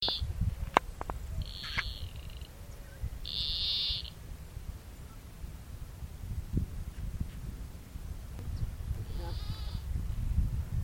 White-tipped Plantcutter (Phytotoma rutila)
Sex: Male
Life Stage: Adult
Location or protected area: Ceibas
Condition: Wild
Certainty: Observed, Recorded vocal